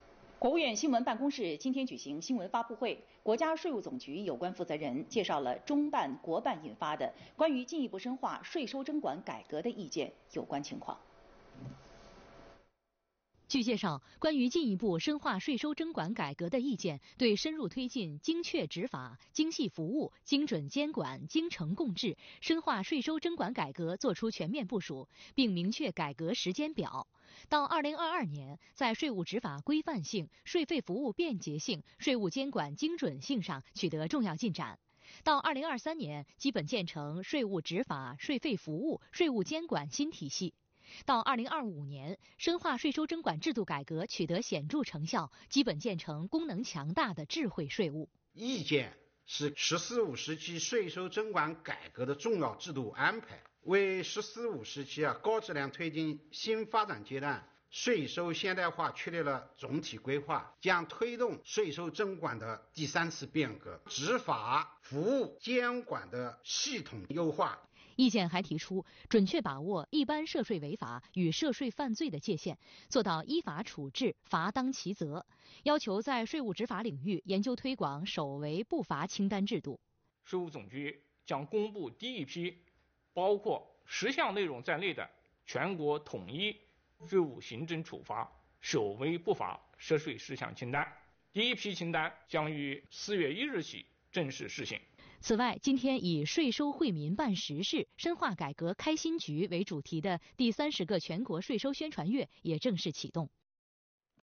视频来源：央视《新闻联播》
国务院新闻办公室今天（3月29日）举行新闻发布会，国家税务总局有关负责人介绍了中办、国办印发的《关于进一步深化税收征管改革的意见》有关情况。